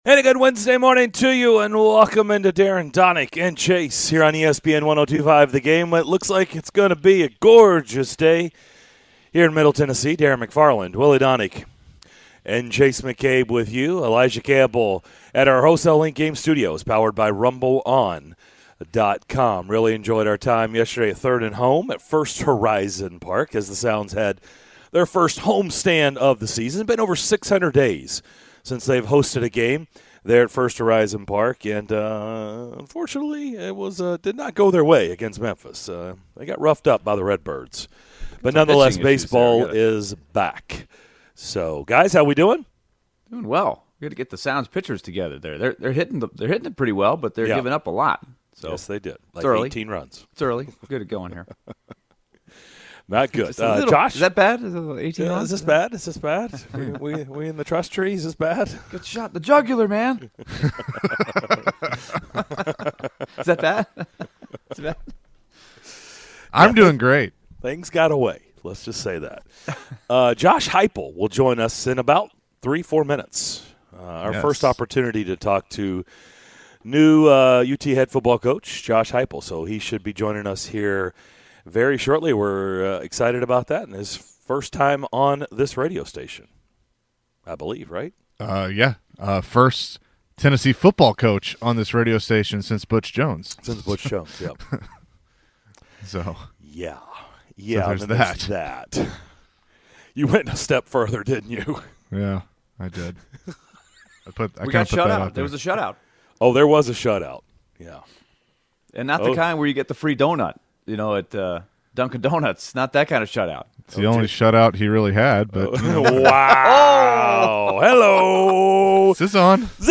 University of Tennessee Head Football Coach Josh Heupel joined the show to discuss his team's progress through spring practice and his adjustment to the new job, the guys debate the college sports transfer rule and more during the first hour of Wednesday's show!